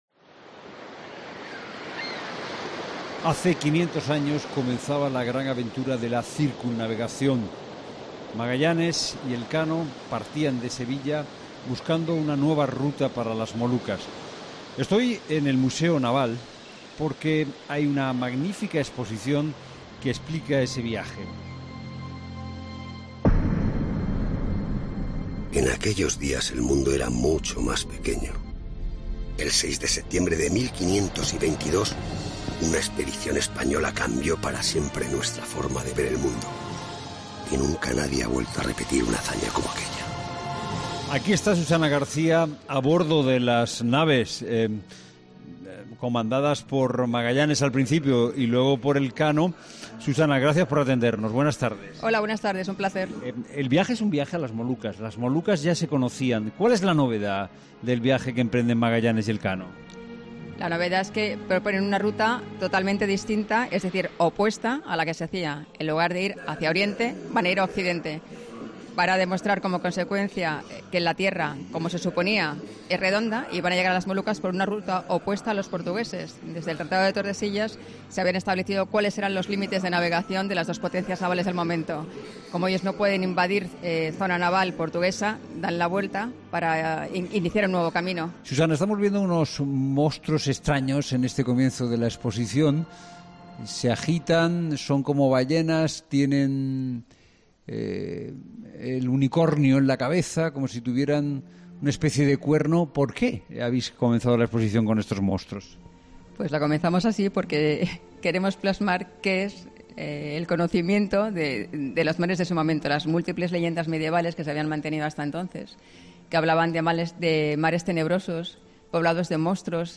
La Tarde recorre la exposición que conmemora los 500 años de la primera vuelta al mundo.